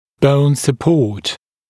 [bəun sə’pɔːt][боун сэ’по:т]окружающая костная ткань, поддерживающая костная ткань